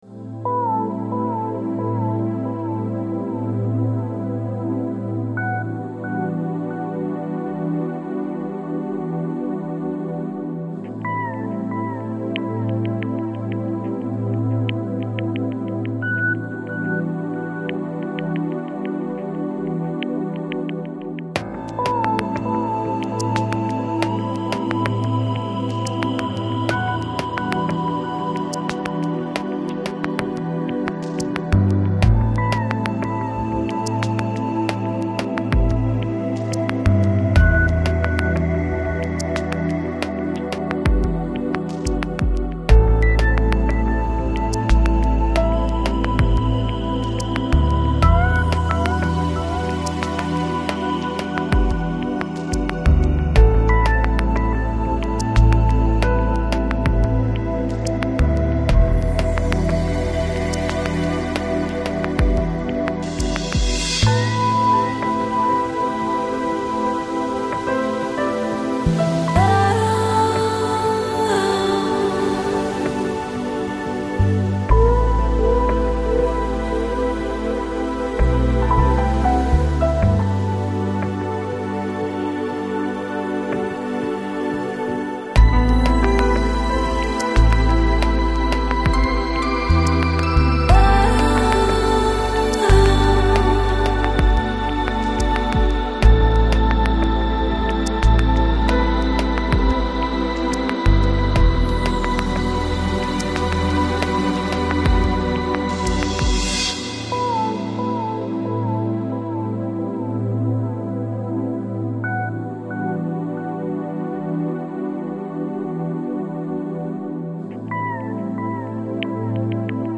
Жанр: Chillout, Lounge, Downtempo